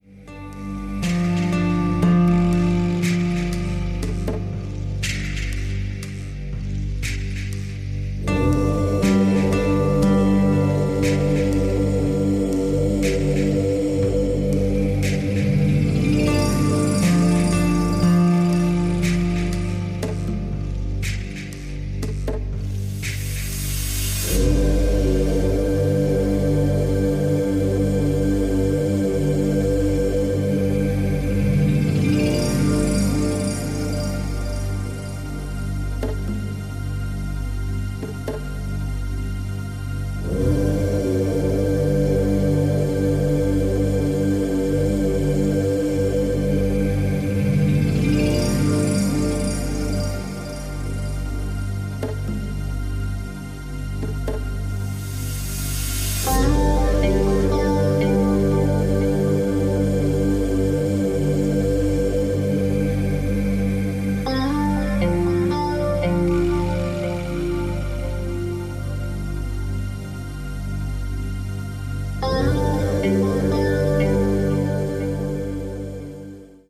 OM – Mantra